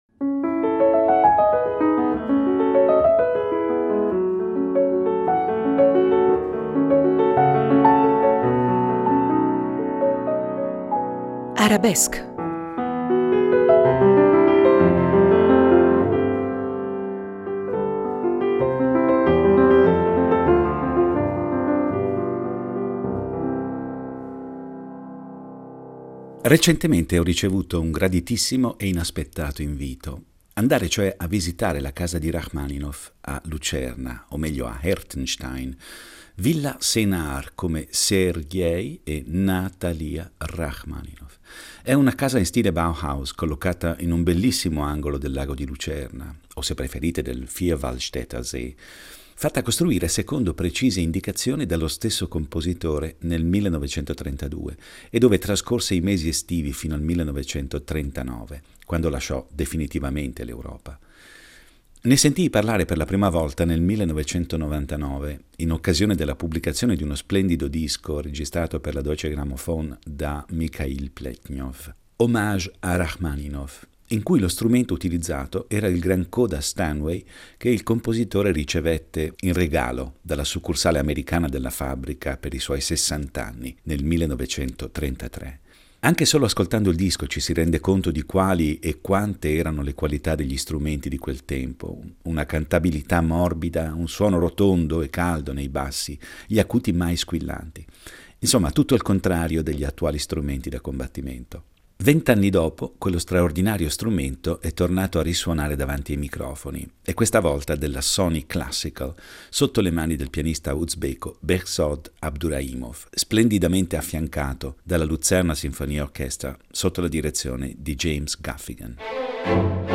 (pron. Bechzòd Abduraìmoff)